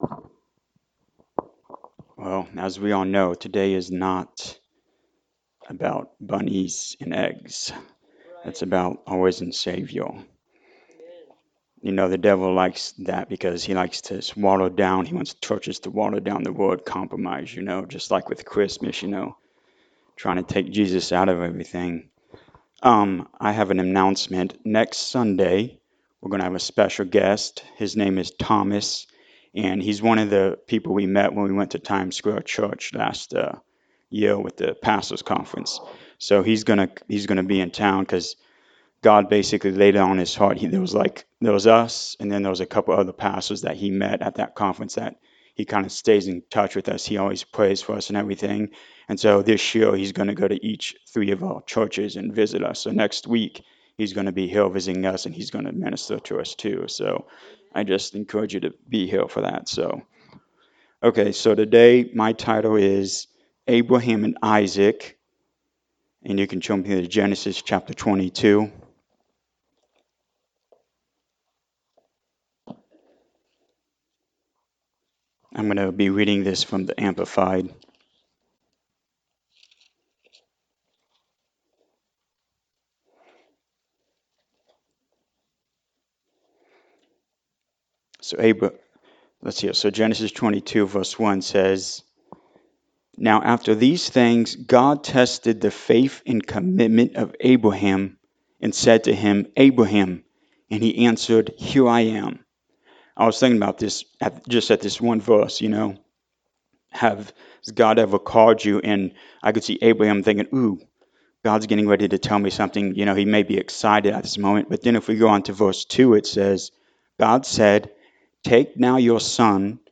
Genesis 22:1-19 Service Type: Sunday Morning Service How many times has God told you what to do and you have questioned Him?